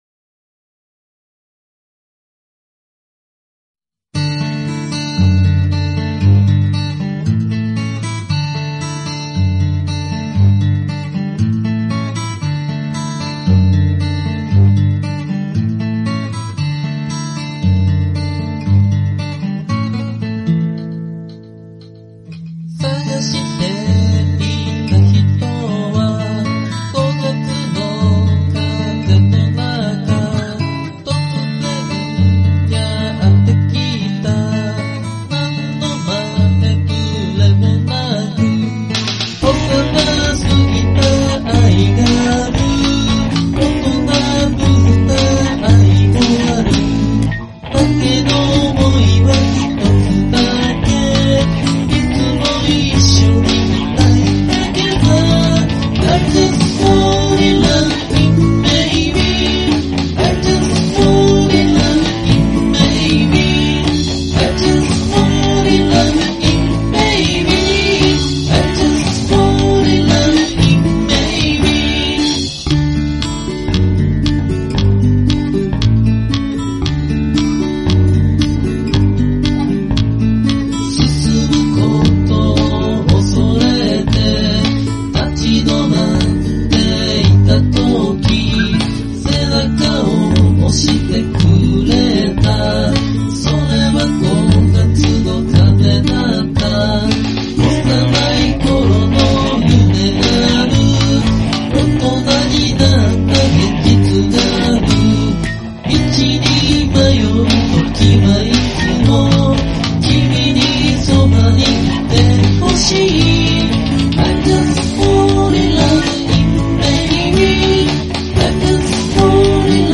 Vocal,Chorus,E.guitar,A.guitar,Bass,Drums
ギターサウンドにこだっわってキーボードレスで 仕上げました。